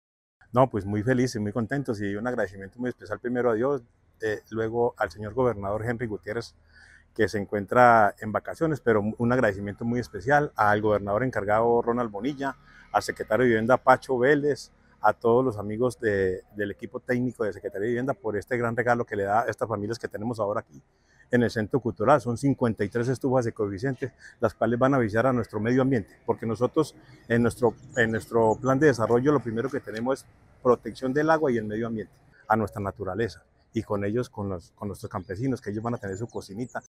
Juan Alberto Vargas Osorio, alcalde de Victoria.
Juan-Alberto-Vargas-Osorio-Alcalde-Victoria-Estufas-ecoeficientes.mp3